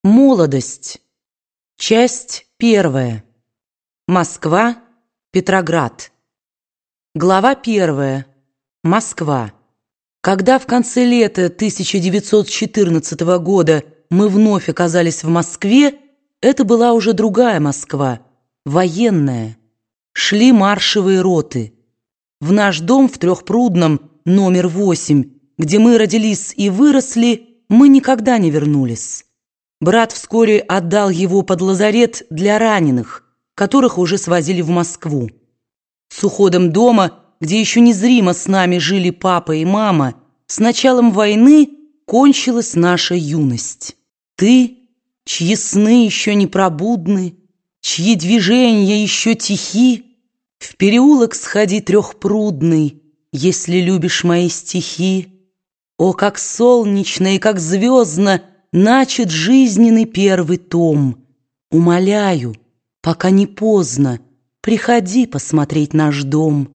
Аудиокнига Воспоминания. Часть третья. Молодость | Библиотека аудиокниг